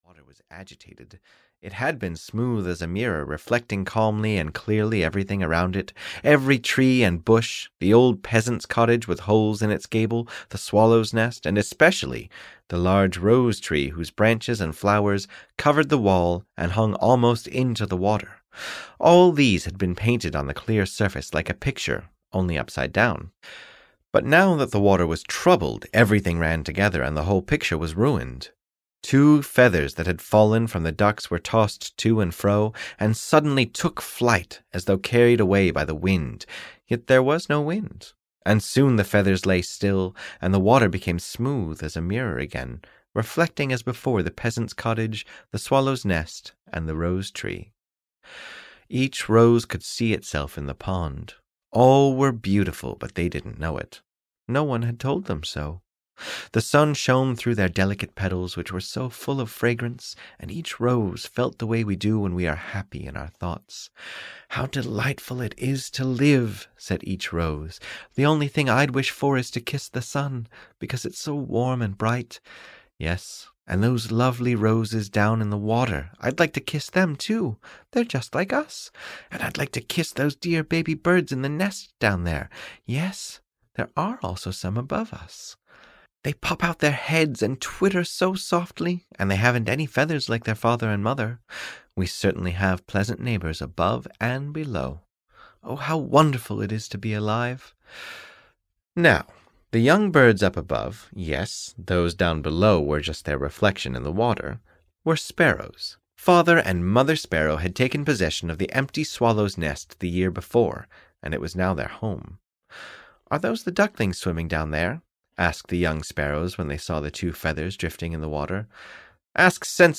The Neighbouring Families (EN) audiokniha
Ukázka z knihy